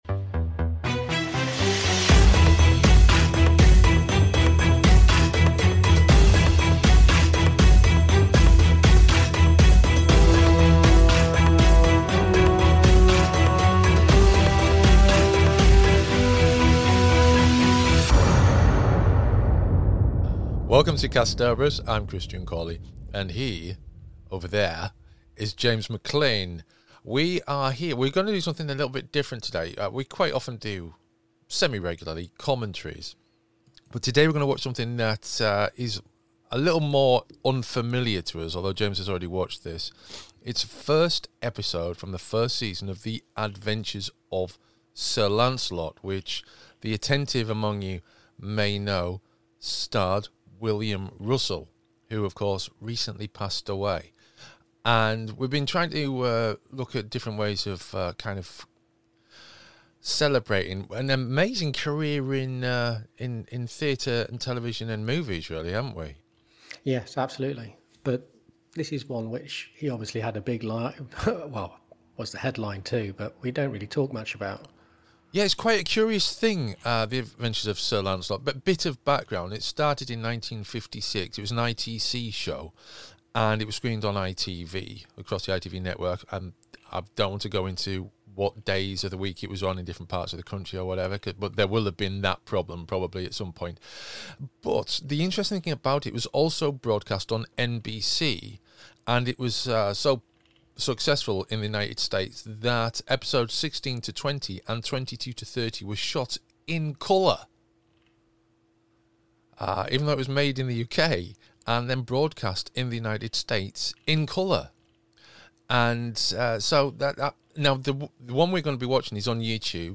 Remembering William Russell - The Adventures of Sir Lancelot Commentary